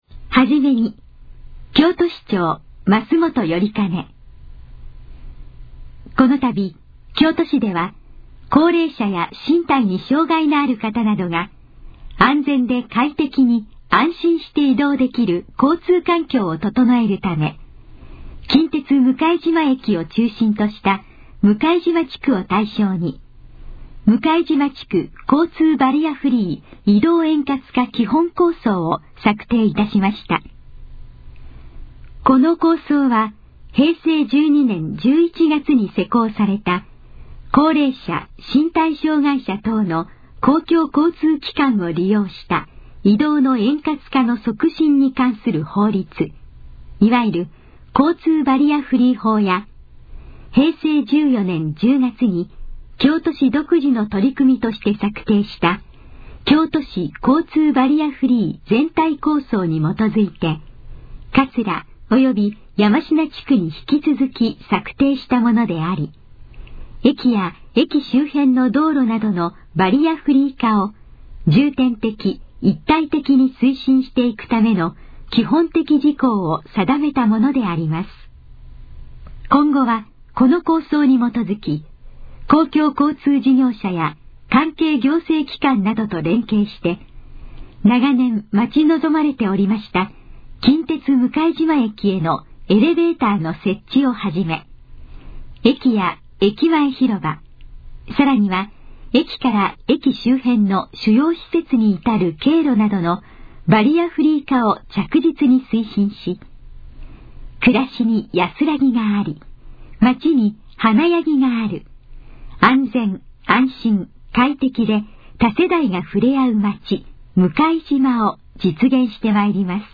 このページの要約を音声で読み上げます。
ナレーション再生 約304KB